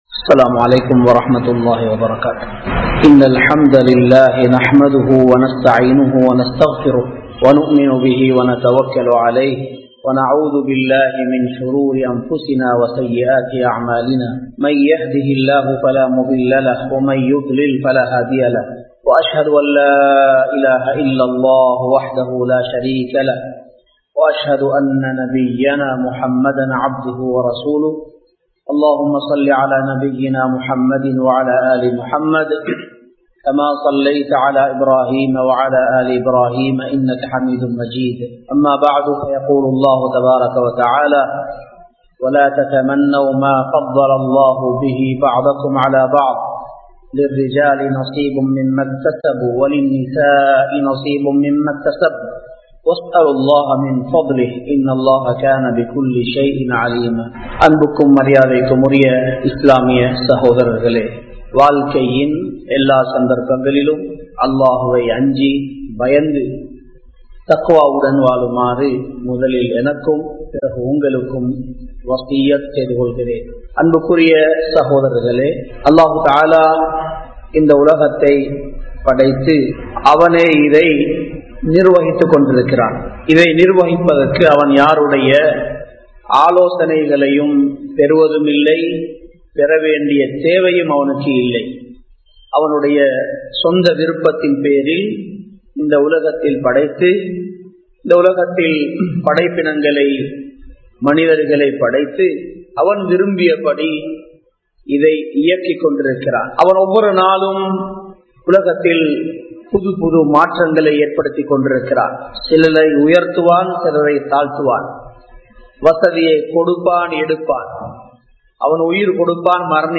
அல்லாஹ்வின் ஆட்சி (The government of Allah) | Audio Bayans | All Ceylon Muslim Youth Community | Addalaichenai
Colombo 07, Jawatha Jumua Masjith